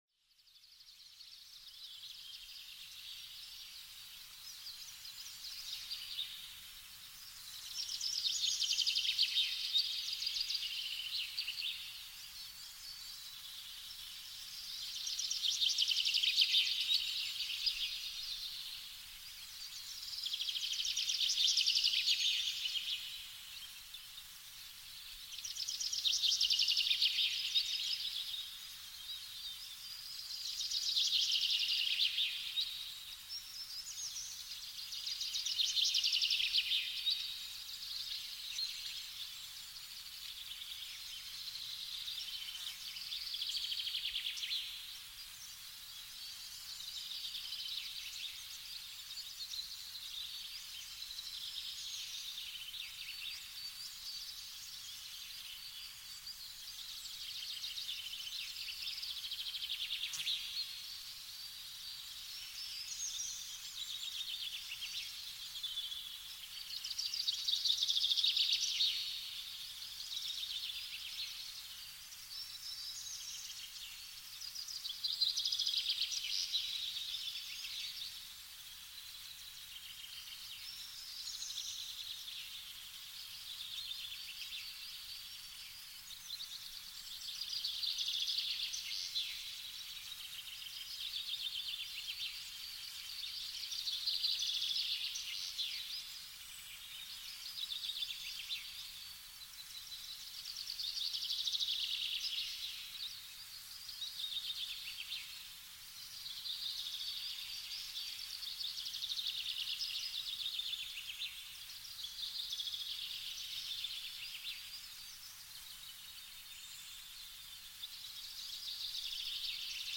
Sommermorgen-Wiesenerwachen: Natur weckt positive entspannende Gedanken